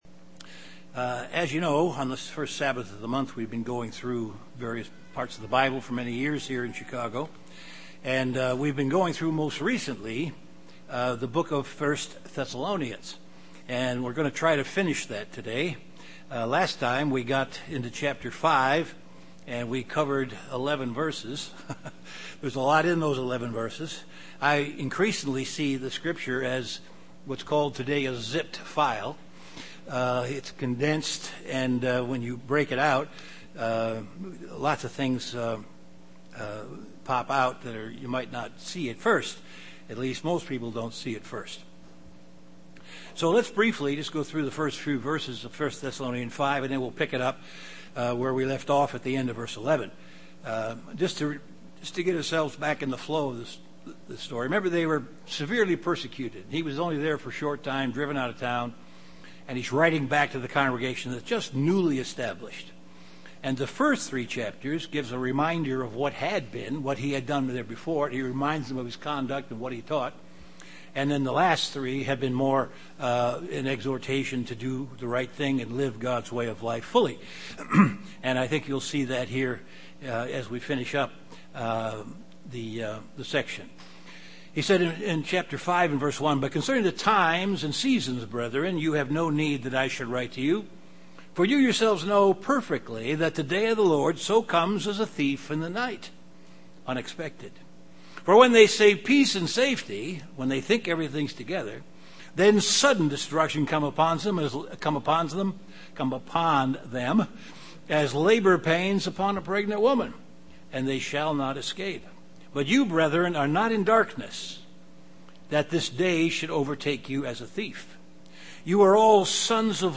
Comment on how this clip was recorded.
Given in Chicago, IL Beloit, WI